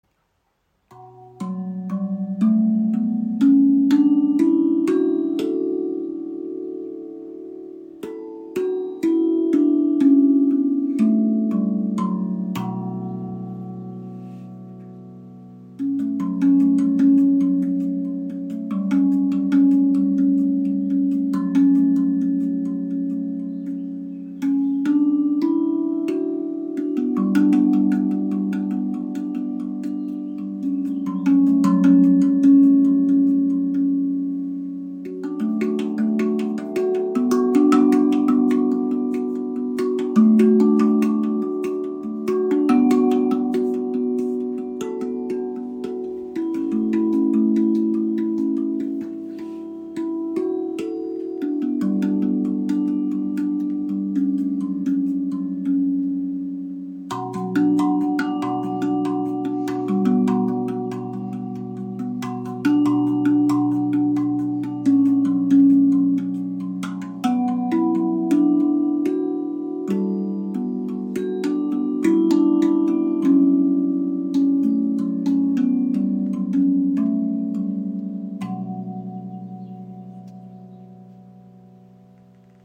Erlebe die ARTQUINT Hybrid Drum – eine harmonische Verbindung aus Handpan und Zungentrommel. Warmer Klang, edles Design, verschiedene Stimmungen.
Klangbeispiel
Die B Kurd entfaltet einen Klang voller Tiefe, Nostalgie und Ausdruckskraft. Inspiriert von der Musiktradition des Nahen Ostens, bewegt sie sich zwischen Licht und Schatten, zwischen Leichtigkeit und Schwere.